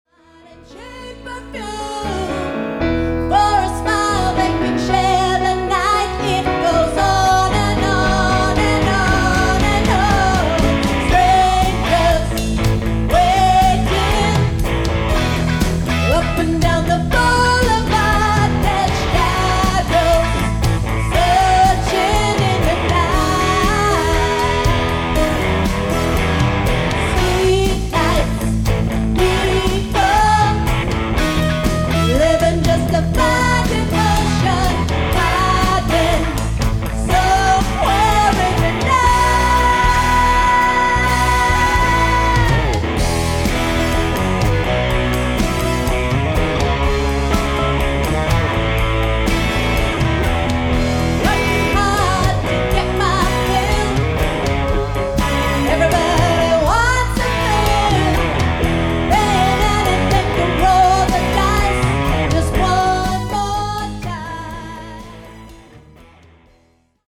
Lead Guitar / Vocals
Bass Guitar / Vocals